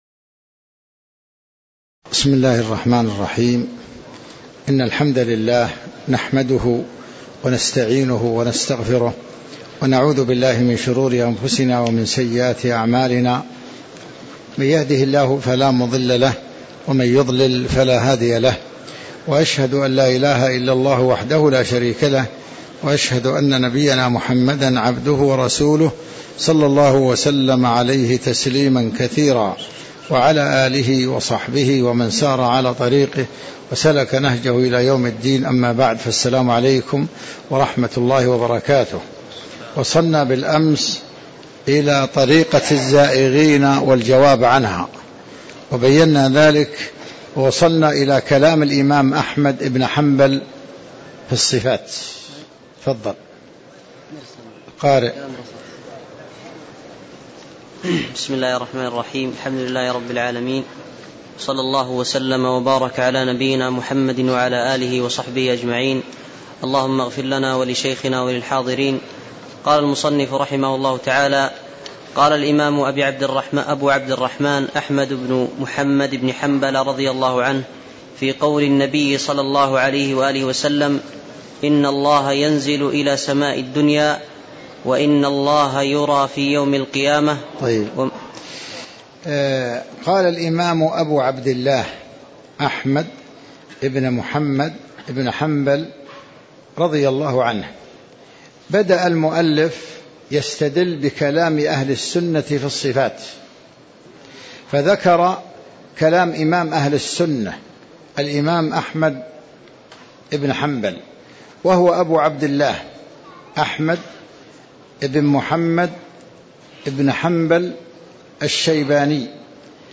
تاريخ النشر ٤ جمادى الآخرة ١٤٣٧ هـ المكان: المسجد النبوي الشيخ